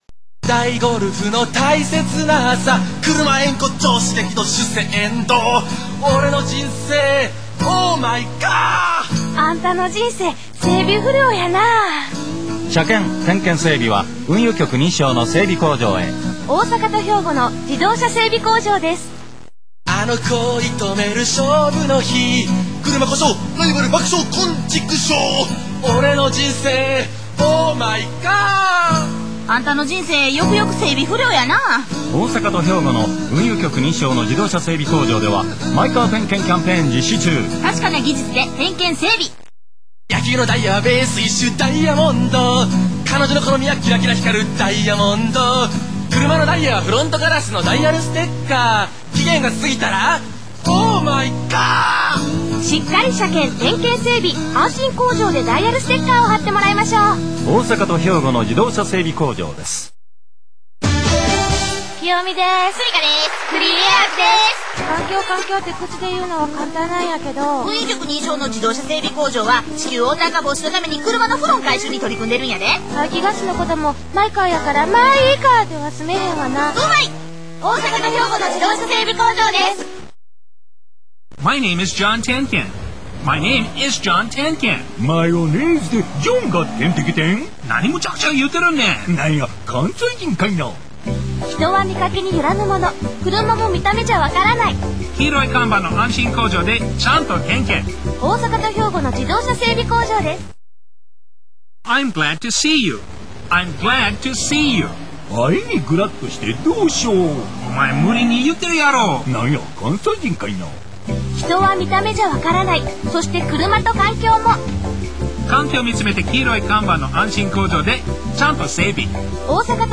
放送内容 ２０秒のスポットＣＭ(６タイプ)